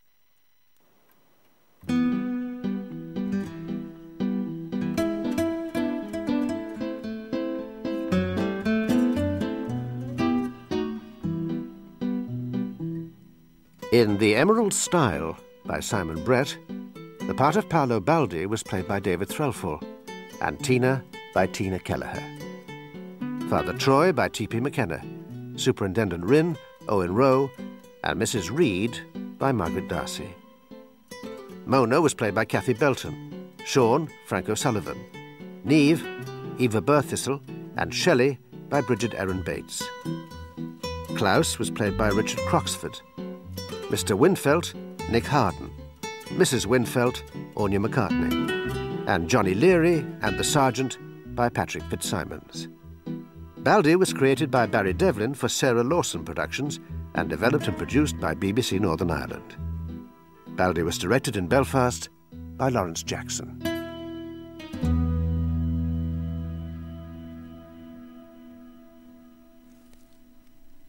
Theme tune
played on acoustic guitar